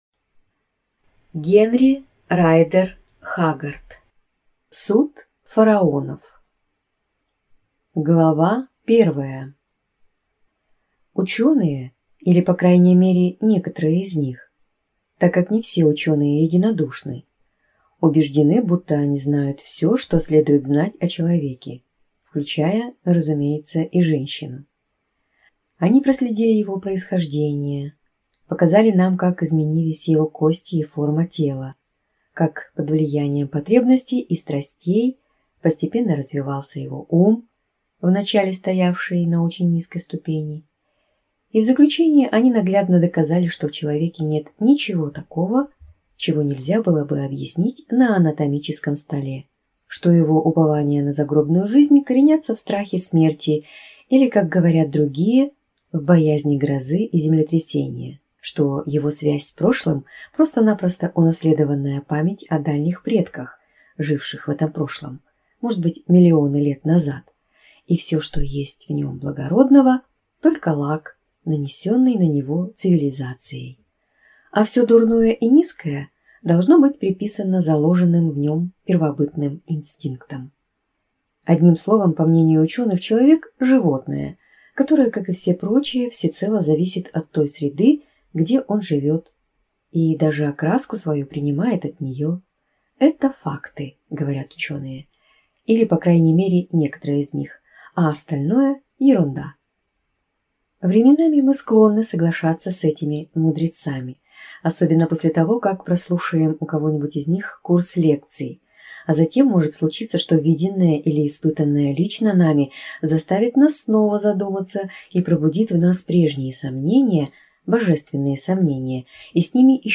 Аудиокнига Суд фараонов | Библиотека аудиокниг